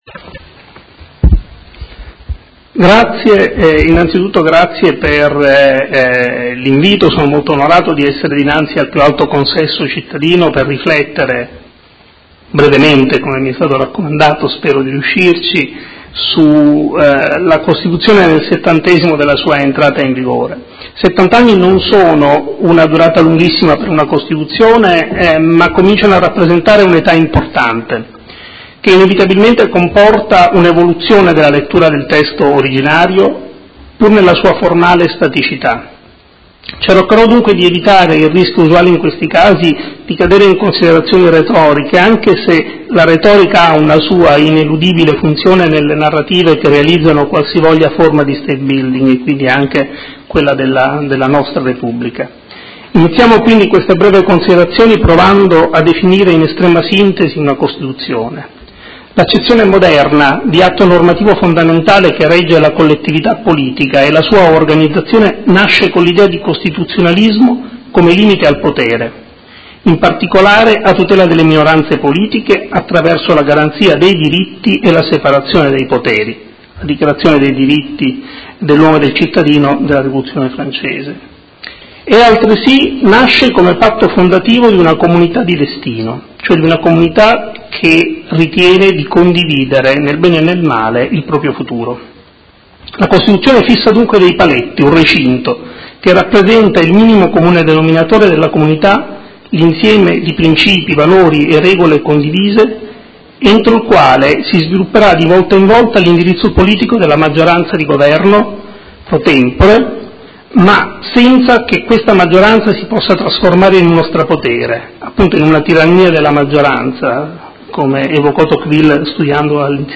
Seduta del 31/05/2018 Festa della Repubblica.